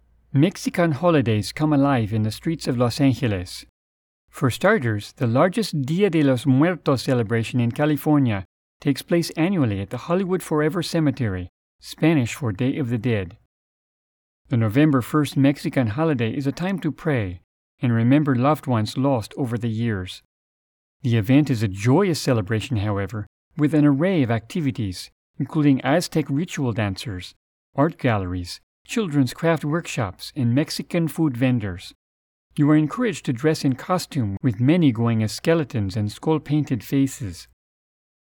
Mature Adult, Adult
Has Own Studio
My voice can be described as deep, smooth, fatherly, and kind, with an authoritative, story-teller vocal style.
Mexican_Accent.mp3